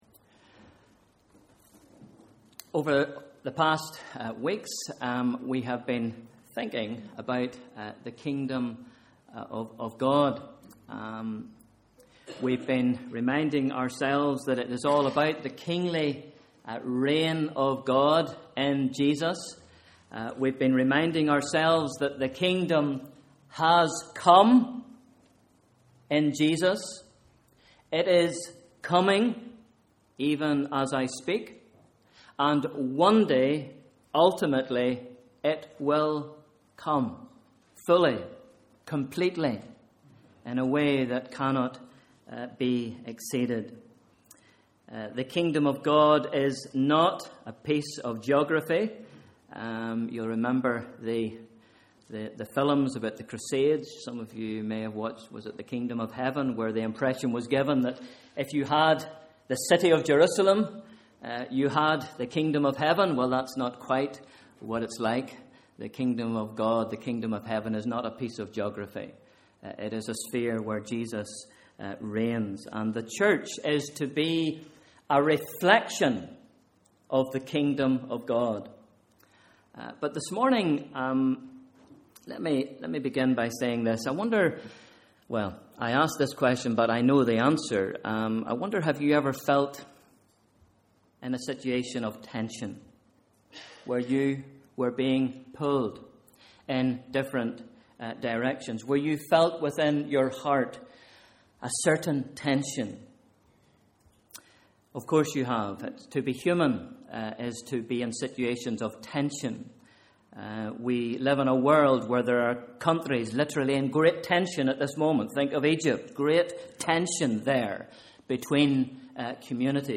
Morning Service: Sunday 28th July 2013